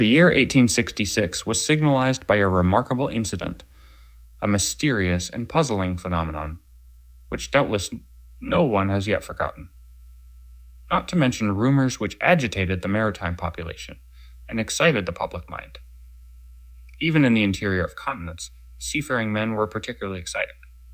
在揭示了剪辑是AI产生的后，他们确实指出，演讲的起搏和速度确实有些偏离，他们相信他们会发现音频并不是真实的剪辑，因为较长的剪辑却不是真实的。
第一个样本是对现实生活中的人，您不起眼的秃鹰的录制，从H.G. Wells的《 Time Machine》中读到，而第二个是AI生成的克隆人从Jules Verne的20,000个海底的联赛中阅读。
AI使用非杂交模型生成音频：